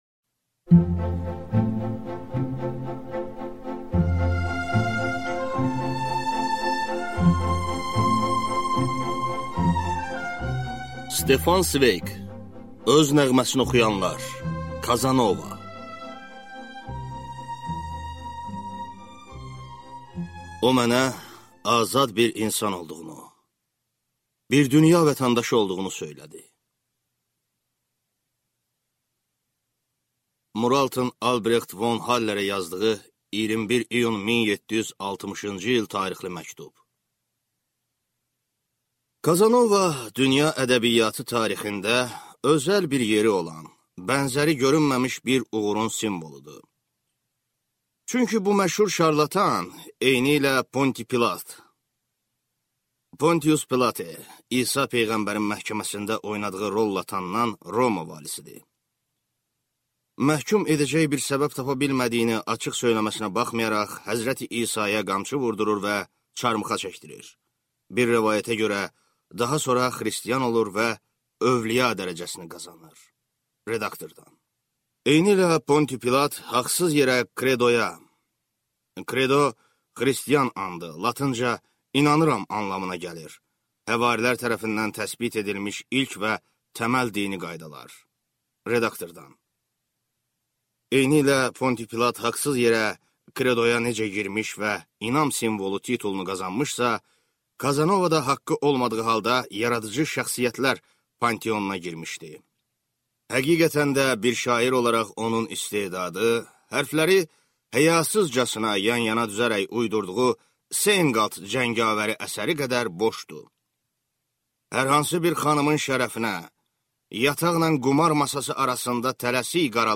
Аудиокнига Kazanova | Библиотека аудиокниг